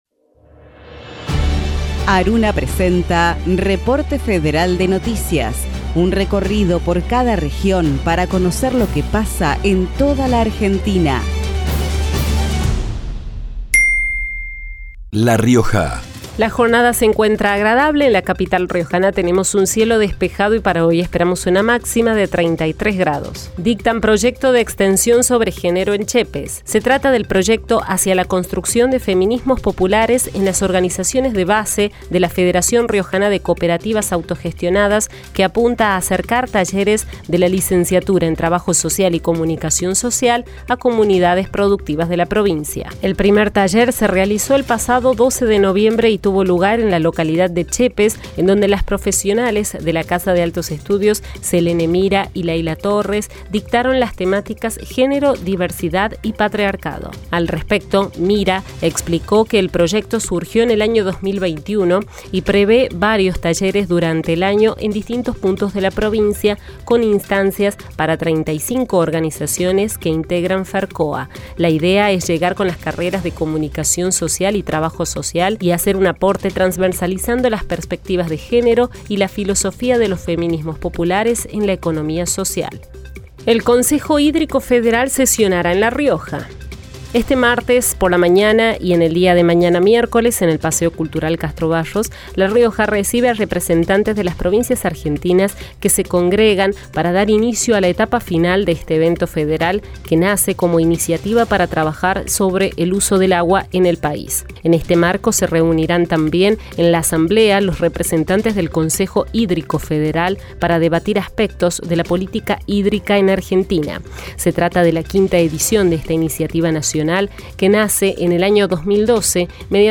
Un programa que contiene dos noticias por cada radio participante, una noticia institucional de las universidades nacionales y otra local o provincial de interés social, con testimonios de las y los protagonistas locales. Un noticiero federal, inclusivo, plural y dinámico, representativo de todas las voces de nuestro país.